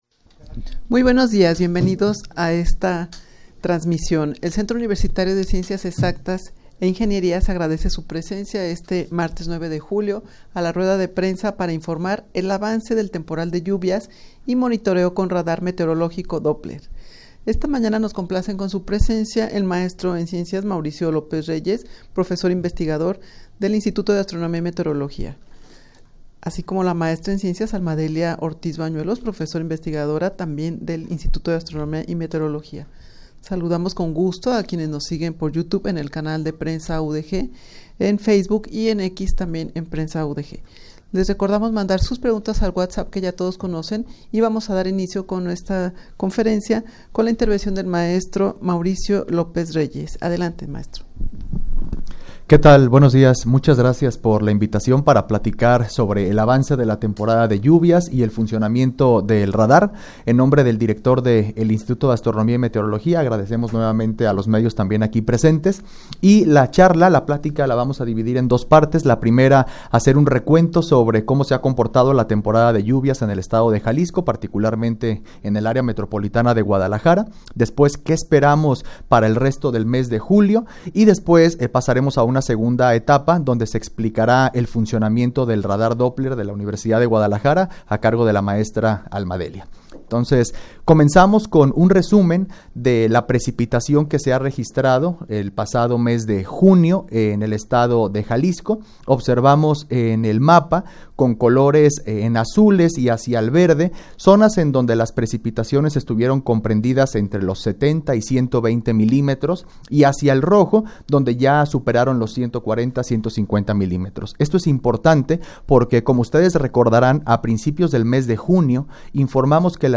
Audio de la Rueda de Prensa
rueda-de-prensa-para-informar-el-avance-del-temporal-de-lluvias-y-monitoreo-con-radar-meteorologico-doppler.mp3